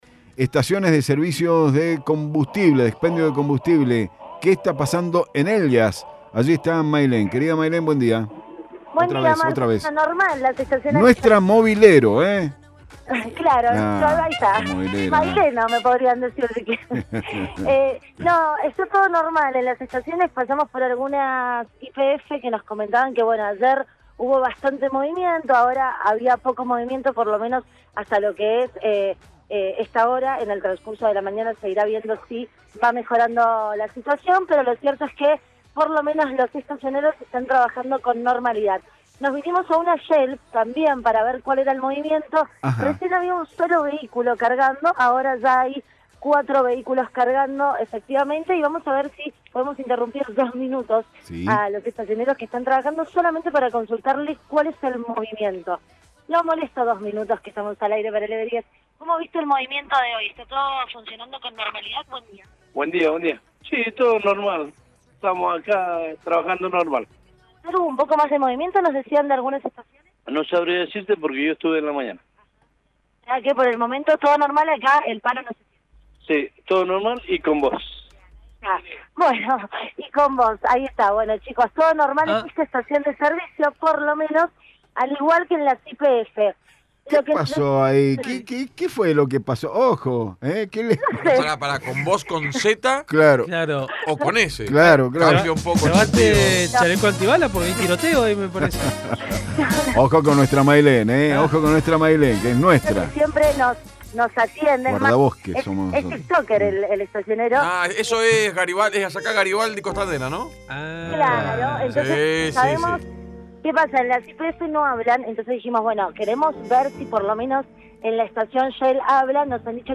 LVDiez - Radio de Cuyo - Móvil de LVDiez - Paro: Estaciones de servicio trabajan con normalidad